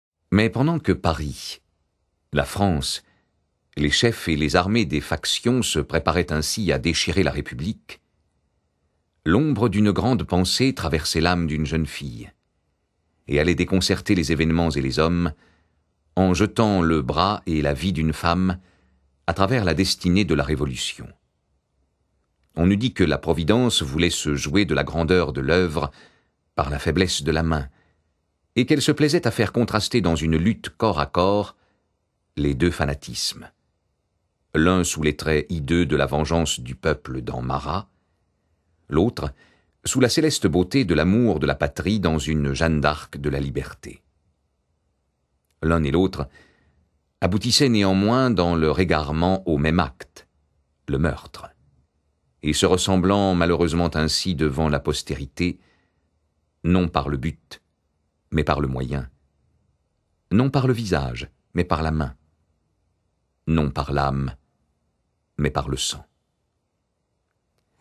Lire un extrait - Histoire de Charlotte Corday de Alphonse De Lamartine